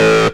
tekTTE63023acid-A.wav